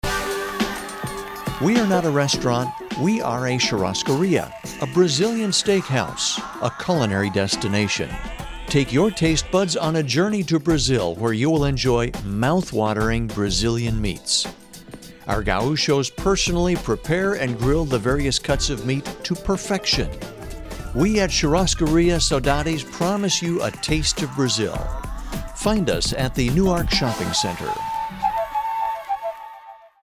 RADIO SPOTS
RadioSpot.mp3